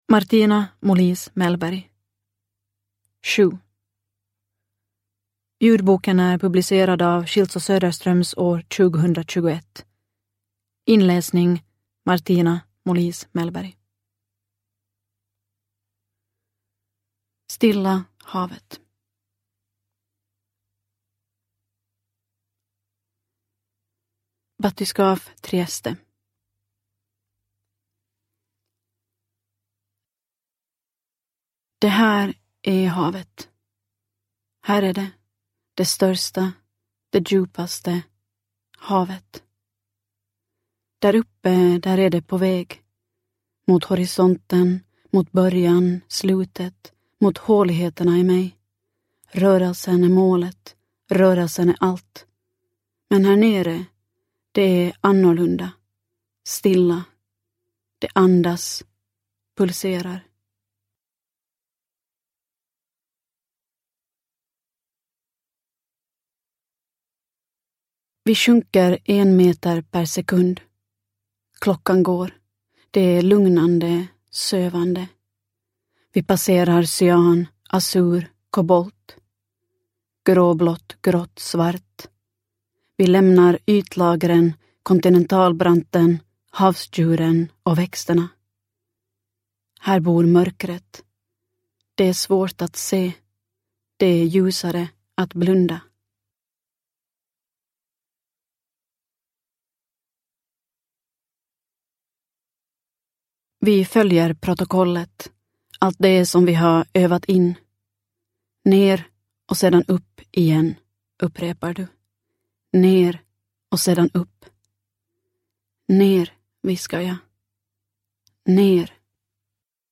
7 – Ljudbok – Laddas ner